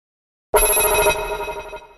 Category: HTC Ringtones